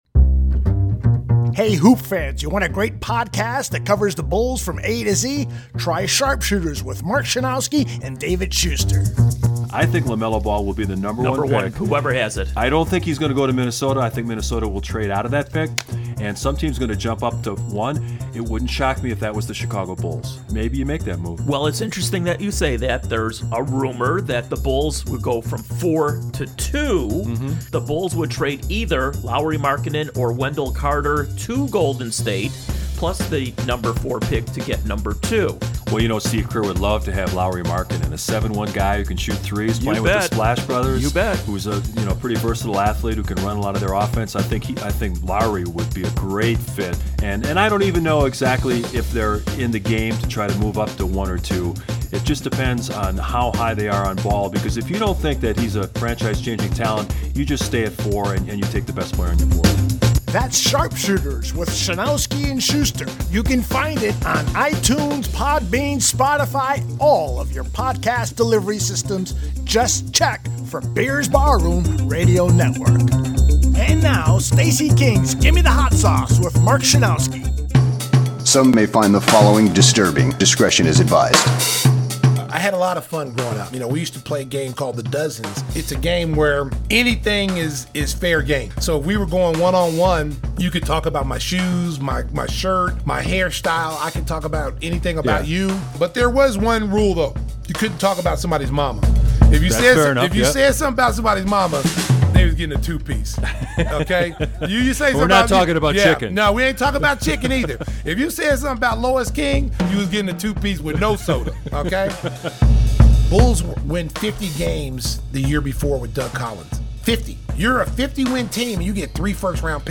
Neil Funk is Stacey King's guest and the two tight friends talk about their time together in the broadcast booth.&nbsp
And, of course, you know the master storyteller, Stacey King, delivers great story after story, too.